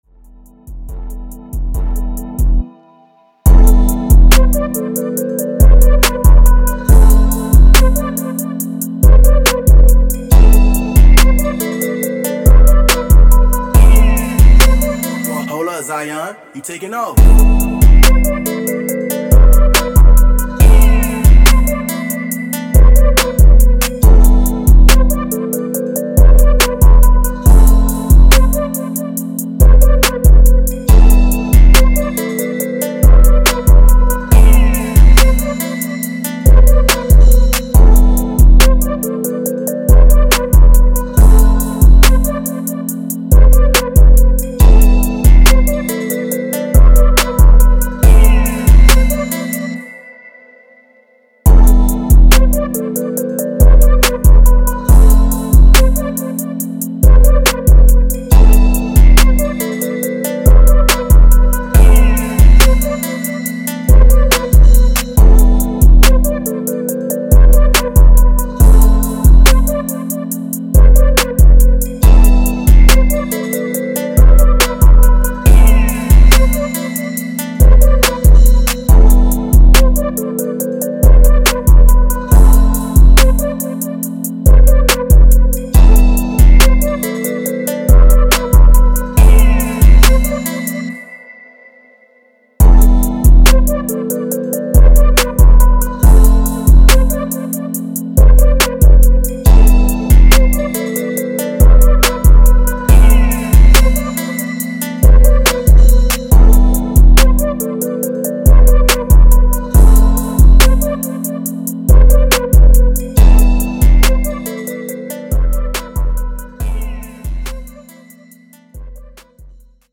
Trap
140 A Minor